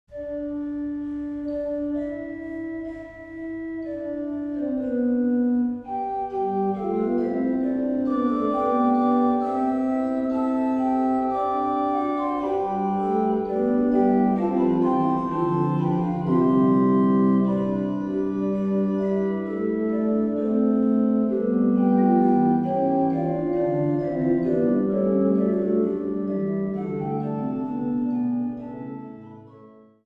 Die Orgeln im St. Petri Dom zu Bremen
Orgel